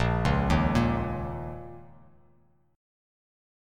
A#mbb5 chord